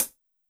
BAL Closed Hat.wav